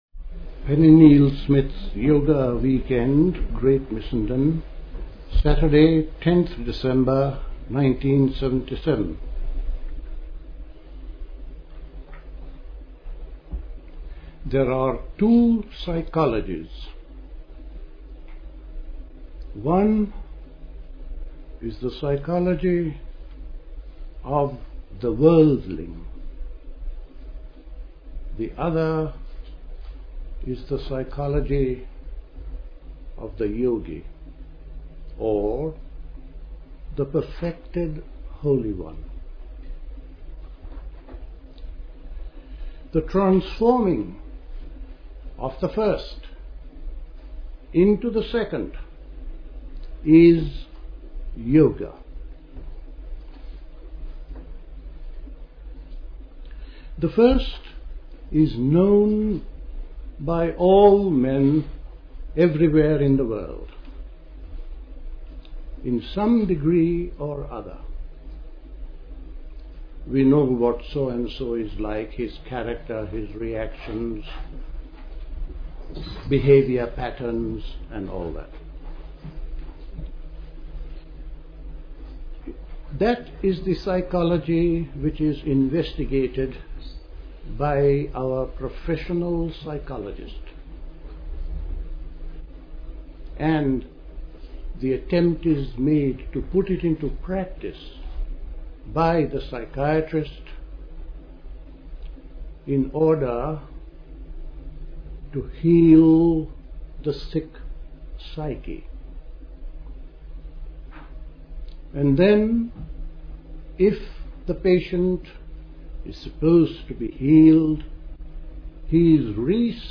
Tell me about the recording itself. Recorded at Missenden Abbey Yoga Weekend.